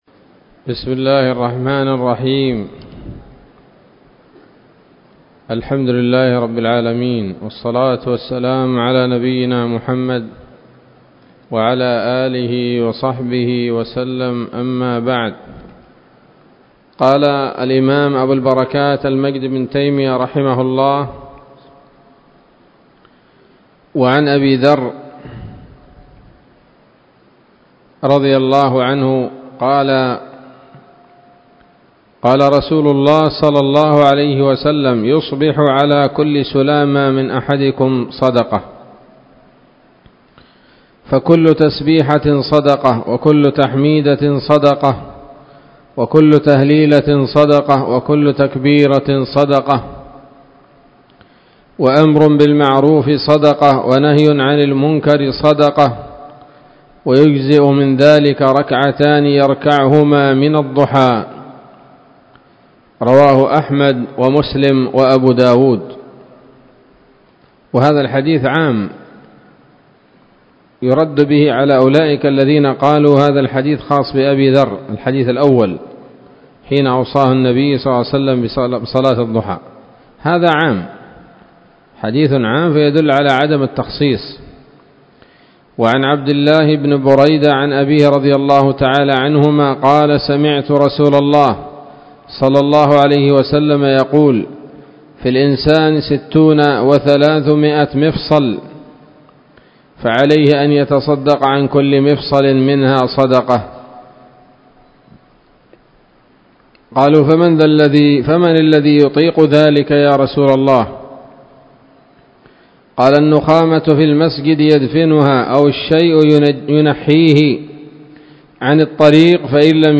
الدرس الحادي والثلاثون من ‌‌‌‌أَبْوَابُ صَلَاةِ التَّطَوُّعِ من نيل الأوطار